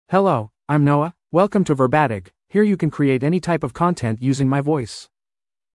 Noah — Male English (United States) AI Voice | TTS, Voice Cloning & Video | Verbatik AI
MaleEnglish (United States)
Noah is a male AI voice for English (United States).
Voice sample
Noah delivers clear pronunciation with authentic United States English intonation, making your content sound professionally produced.